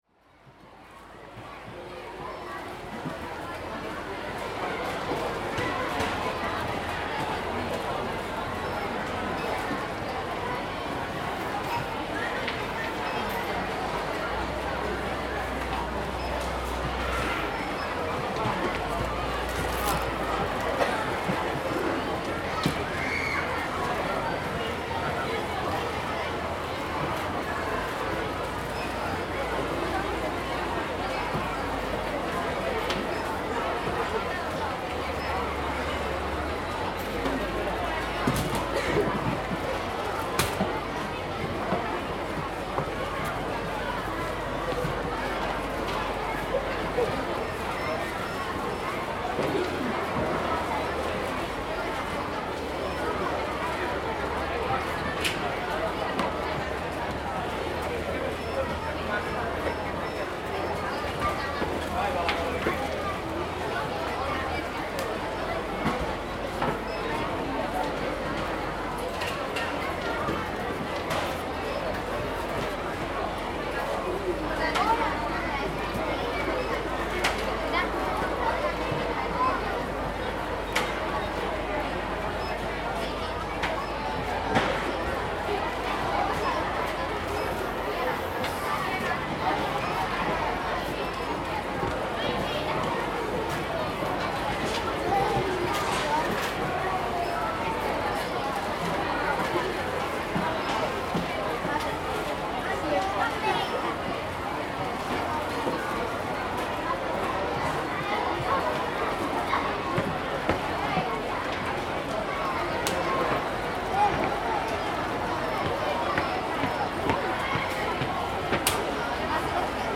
Summer services is the biggest spiritual meeting in Finland and one of the biggest summer festivals gathering somewhat 80000 people every year.
• Soundscape
• announcement